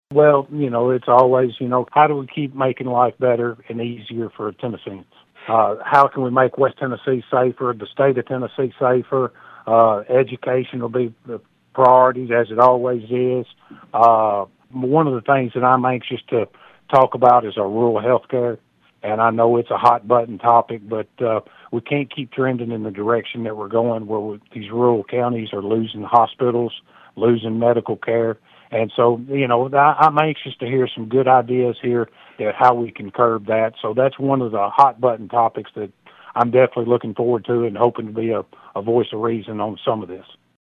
76th District State Representative Tandy Darby talked with Thunderbolt News about the return to session.(AUDIO)